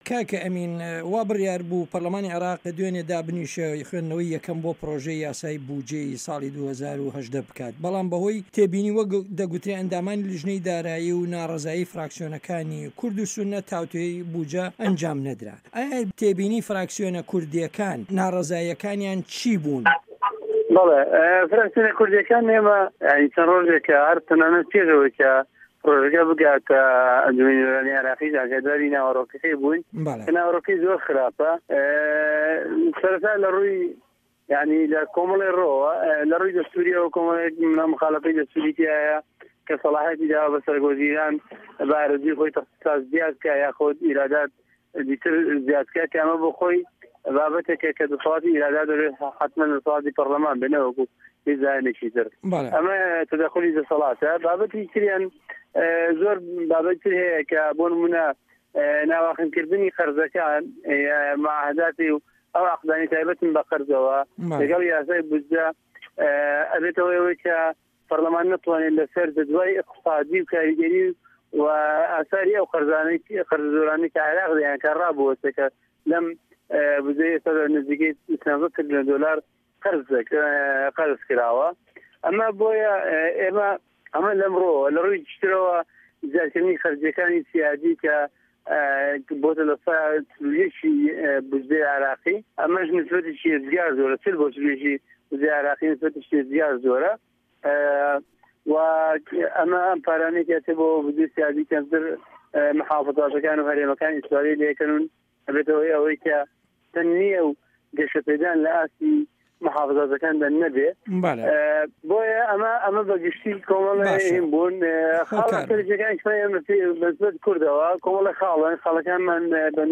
وتوێژ لەگەڵ ئەمین بەکر